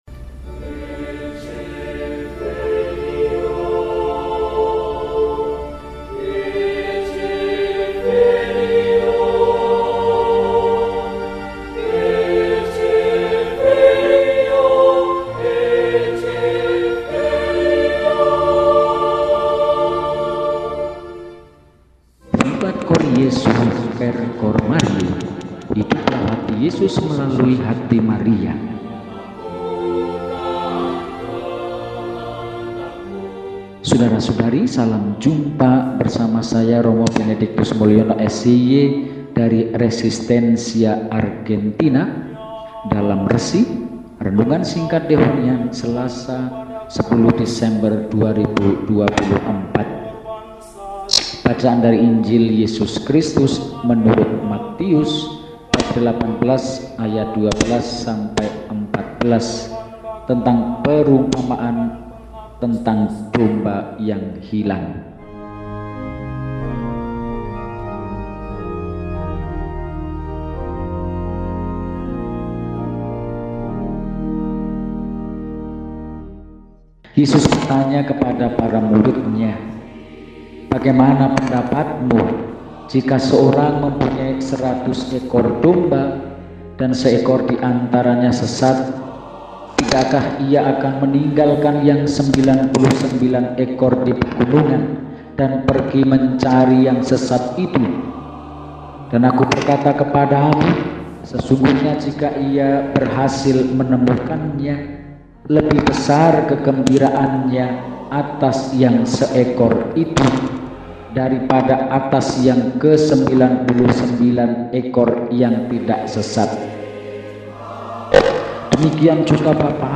Selasa, 10 Desember 2024 – Hari Biasa Pekan II Adven – RESI (Renungan Singkat) DEHONIAN